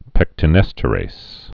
(pĕktə-nĕstə-rās, -rāz)